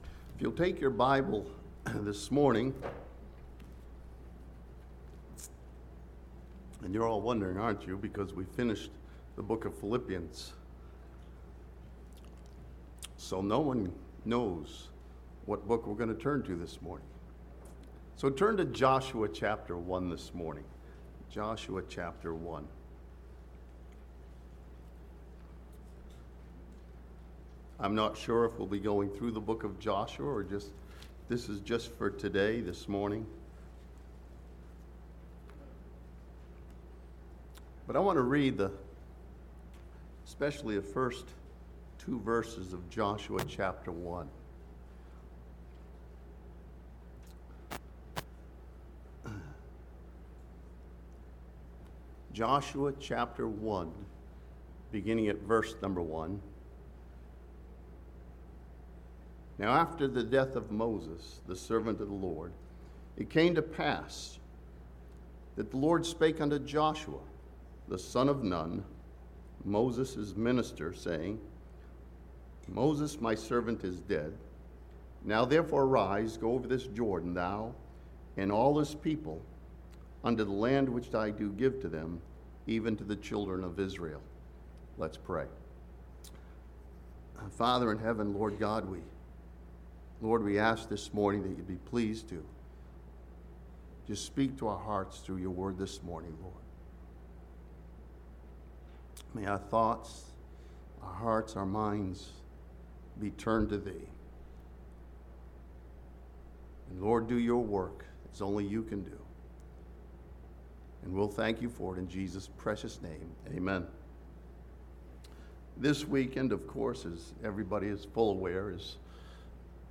This sermon from Joshua chapter 1 studies the destination, day, and direction of Joshua as a lesson for us of finding abundant life.